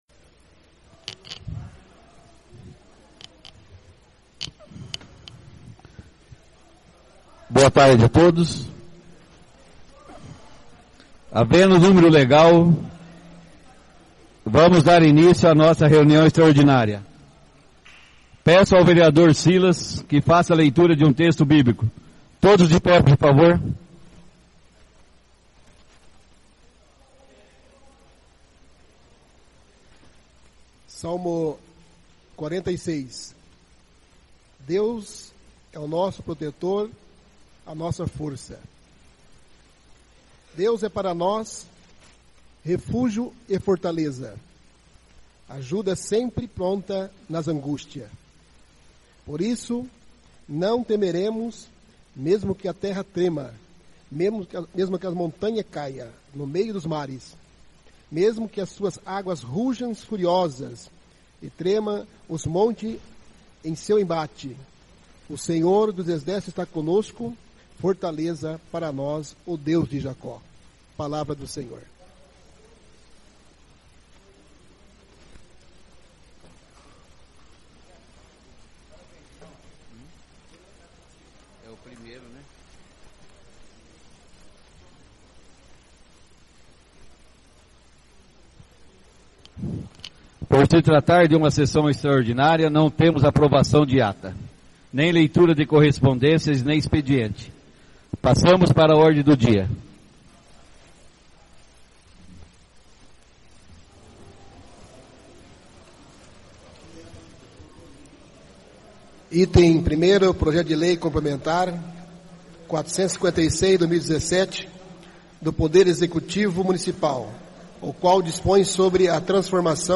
Resumo (6ª Extraordinária da 1ª Sessão Legislativa da 9ª Legislatura)
Tipo de Sessão: Extraordinária
A convite do Senhor Presidente, o edil JOSÉ APARECIDO DA SILVA procedeu à leitura de um texto bíblico.